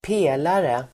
Uttal: [²p'e:lare]